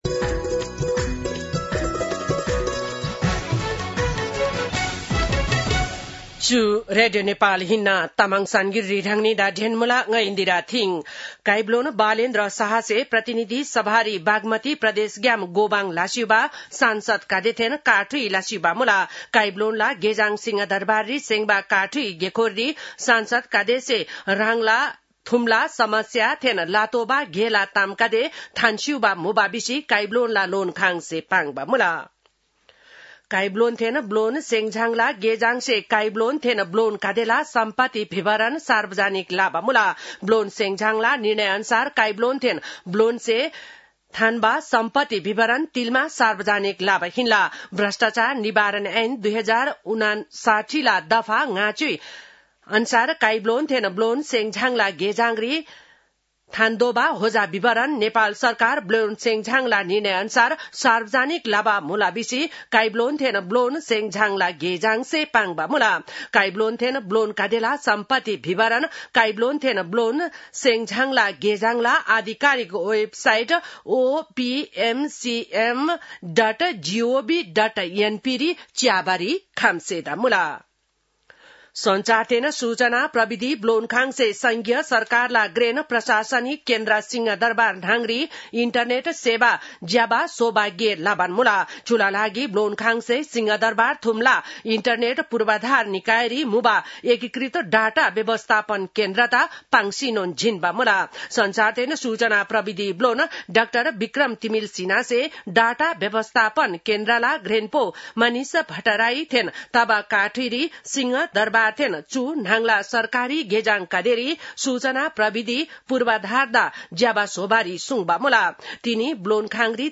तामाङ भाषाको समाचार : ३० चैत , २०८२